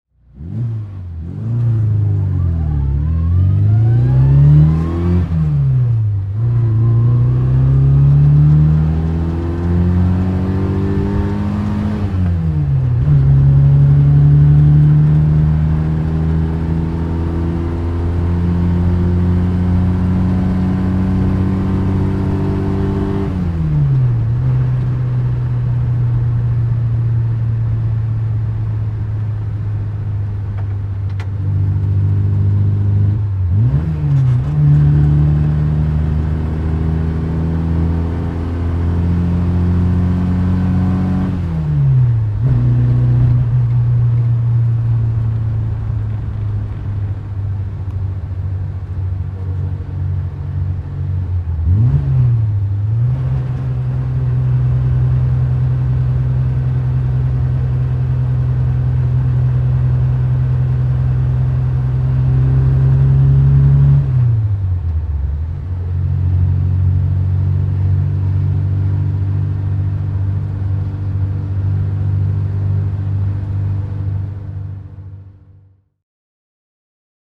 Fiat-Abarth 850 TC Nürburgring (1963) - Innengeräusch